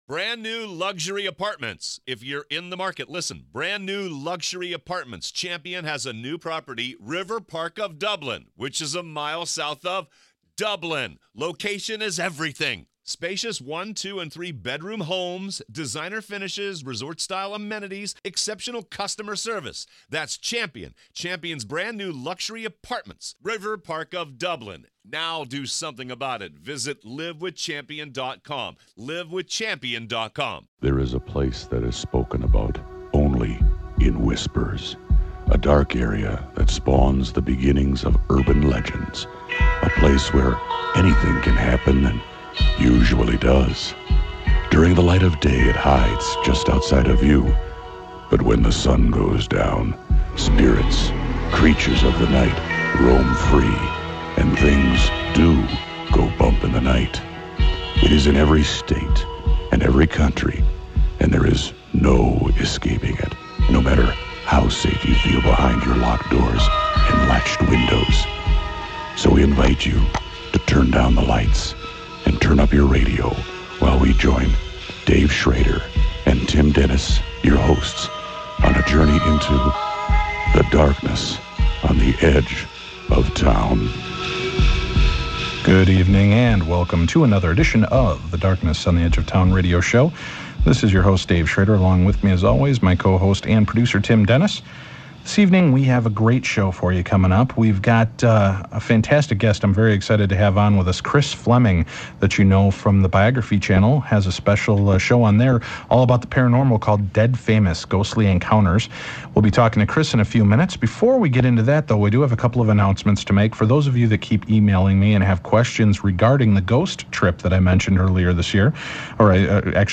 Society & Culture, Hobbies, Leisure, Religion & Spirituality, Spirituality, Philosophy